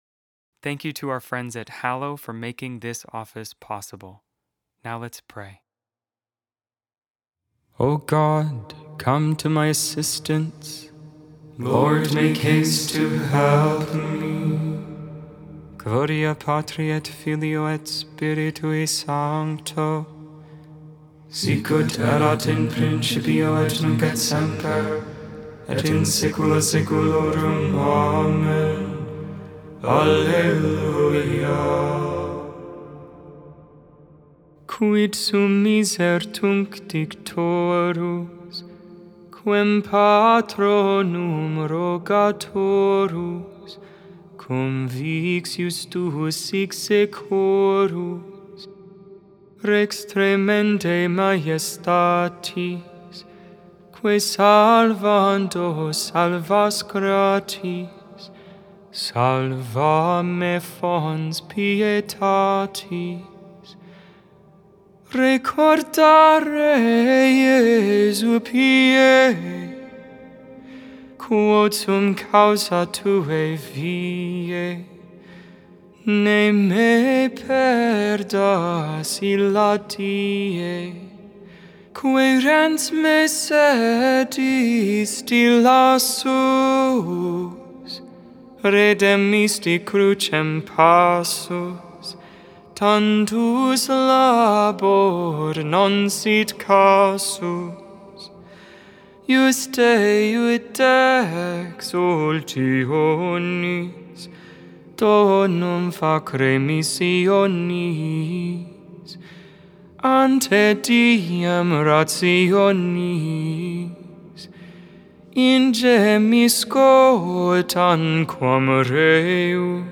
Lauds, Morning Prayer for the 32nd Friday in Ordinary Time, November 14, 2025.Made without AI. 100% human vocals, 100% real prayer.